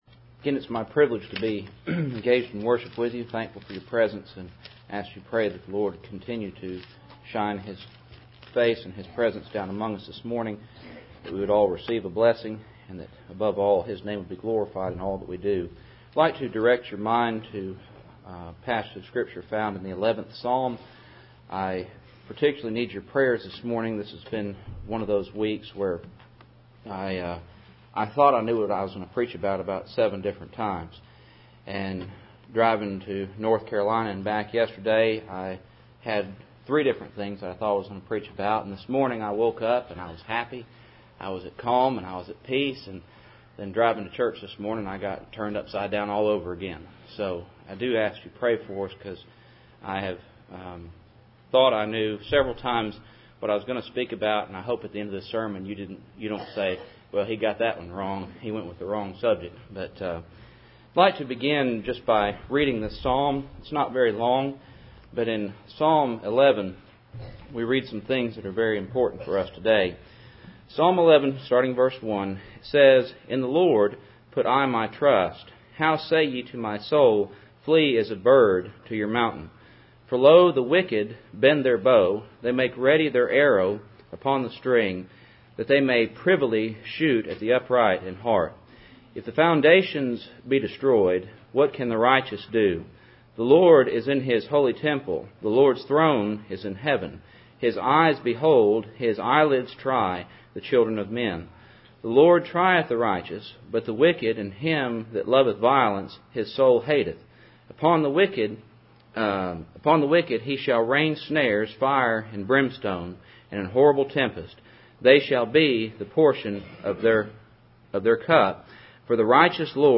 Cool Springs PBC Sunday Morning %todo_render% « Job’s Trial & God’s Sovereignty Simon & A Woman